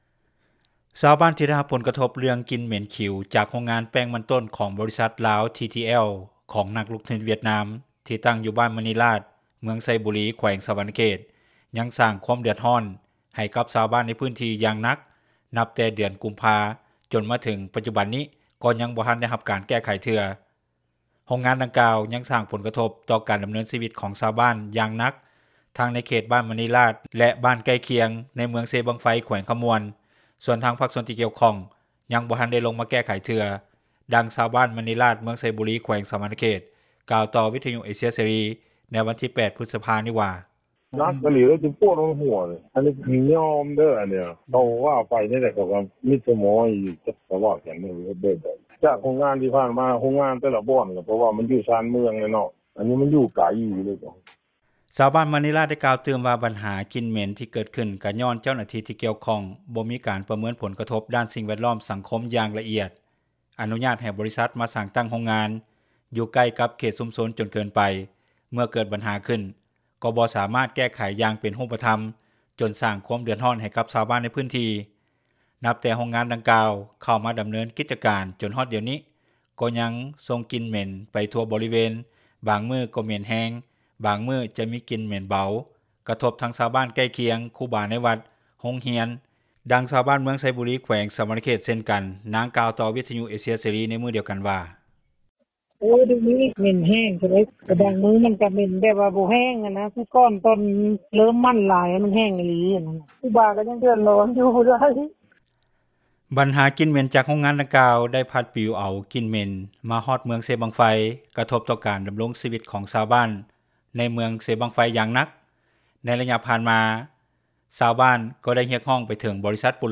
ດັ່ງຊາວບ້ານມະນີລາດ ເມືອງໄຊບູລີ ແຂວງສະຫວັນນະເຂດ ກ່າວຕໍ່ວິທຍຸເອເຊັຽເສຣີ ໃນວັນທີ 08 ພຶດສະພານີ້ວ່າ:
ດັ່ງຊາວບ້ານ ຢູ່ເມືອງໄຊບູລີ ແຂວງສະຫວັນນະເຂດ ເຊັ່ນກັນນາງກ່າວຕໍ່ ວິທຍຸເອເຊັຽເສຣີ ໃນມື້ດຽວກັນວ່າ: